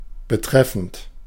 Ääntäminen
IPA: [bəˈtʁɛfn̩t] IPA: [bəˈtʁɛfənt]